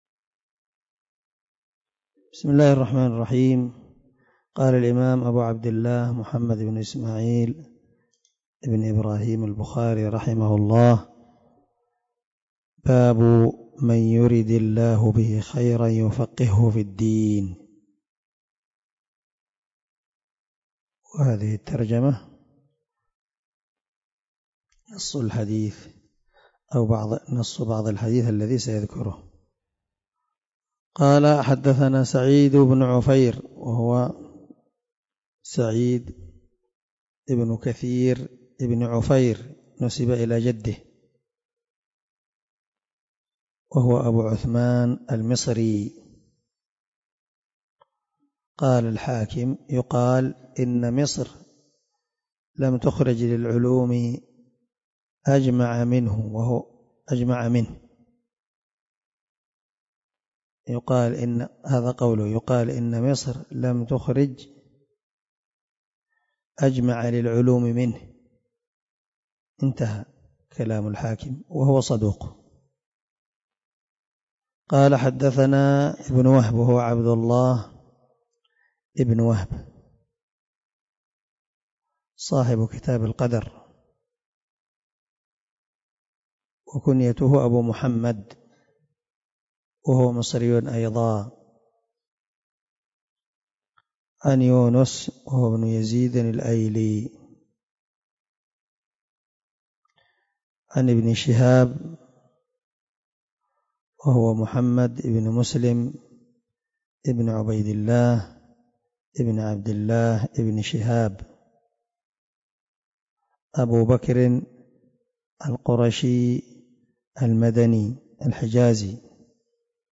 070الدرس 15 من شرح كتاب العلم حديث رقم ( 71 ) من صحيح البخاري